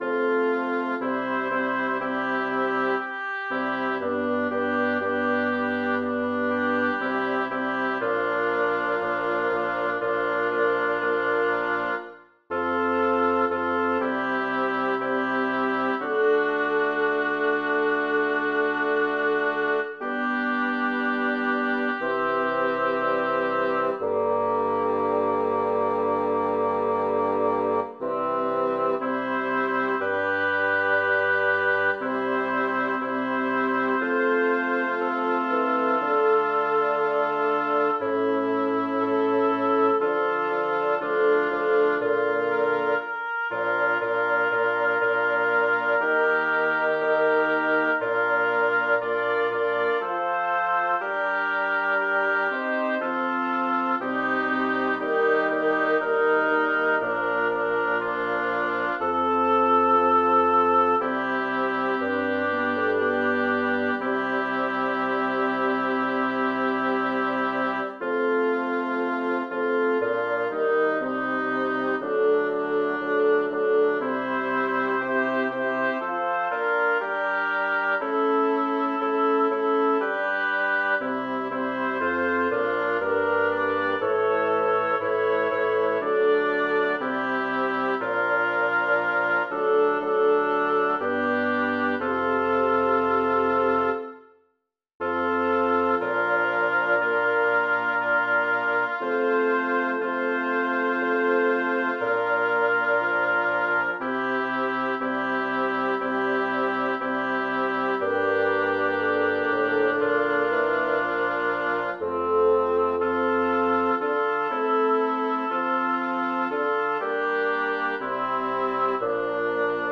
Title: Quos resonat Composer: Jacob Meiland Lyricist: Number of voices: 5vv Voicing: SATTB Genre: Secular, Partsong
Language: Latin Instruments: A cappella